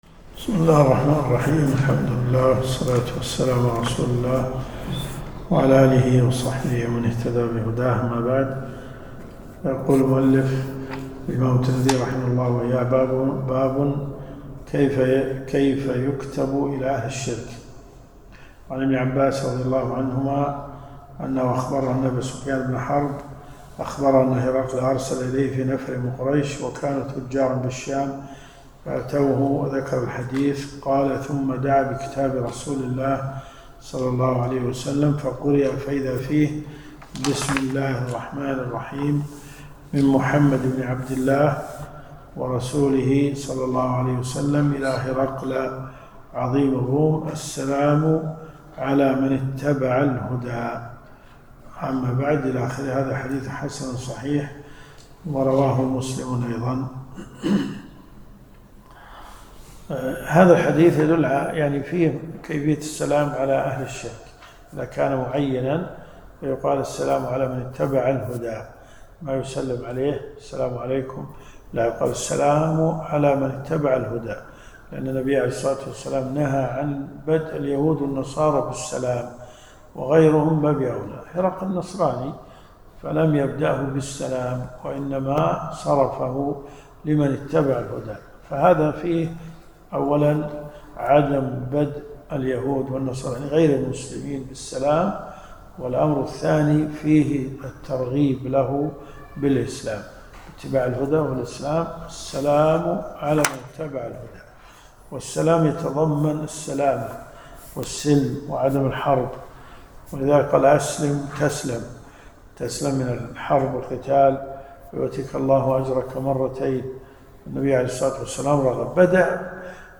الرئيسية الكتب المسموعة [ قسم الحديث ] > جامع الترمذي .